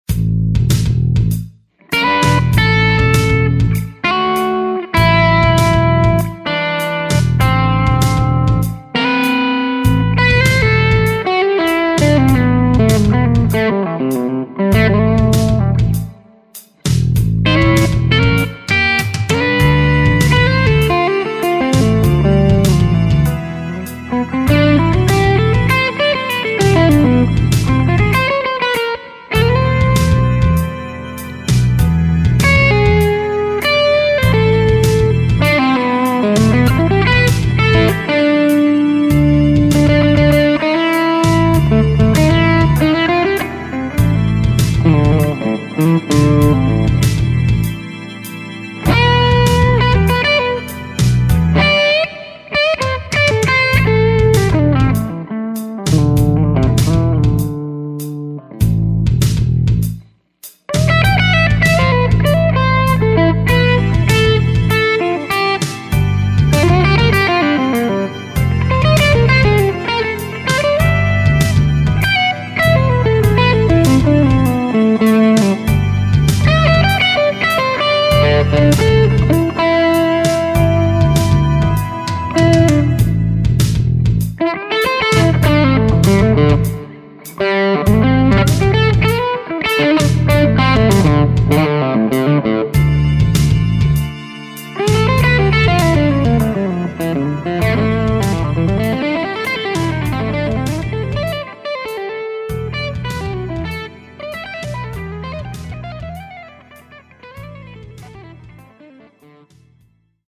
All clips were Eminence RWB speaker and Audix I5 mic.